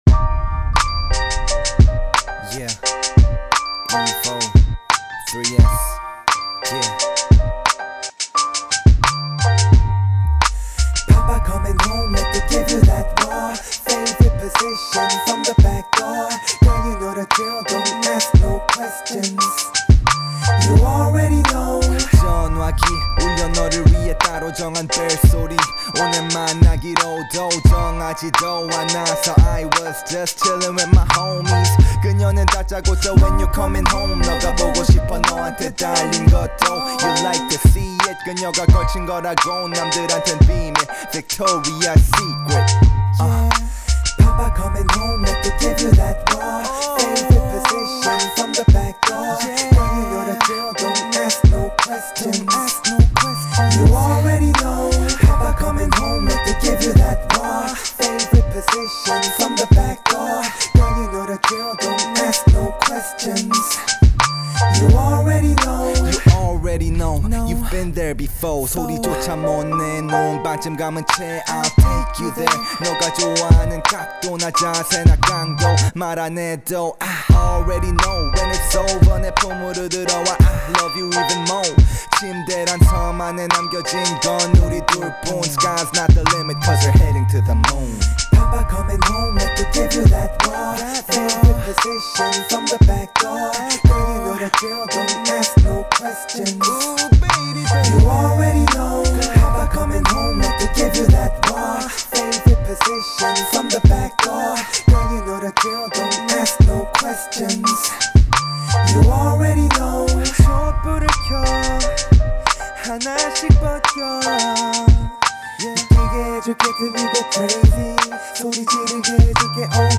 힙합/알앤비 인스트루멘탈에 랩과 노래를